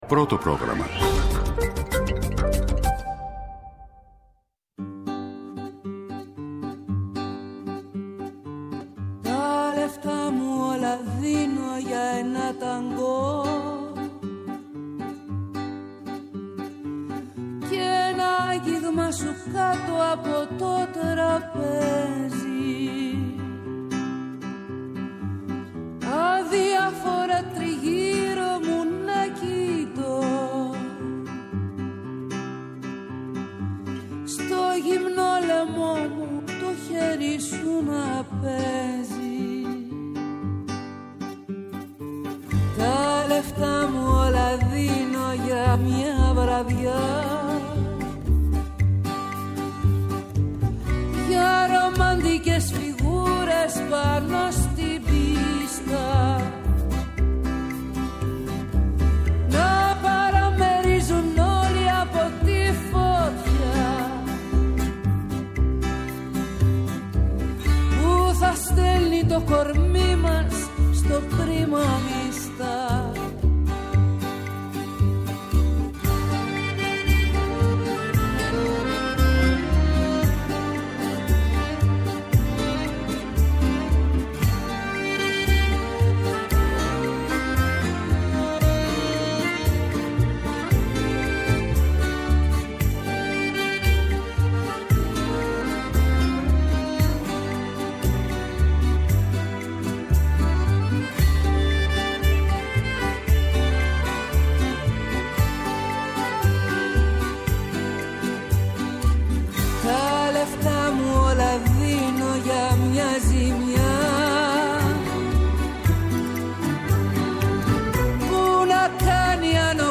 Τα θέματα που μας απασχόλησαν, μέσα από ηχητικά αποσπάσματα, αλλά και συνεντεύξεις.